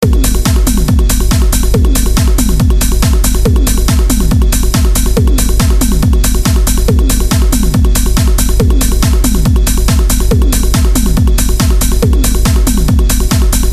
键盘SA10音色库 " 34 大提琴
描述：大提琴
Tag: 大提琴 C 简单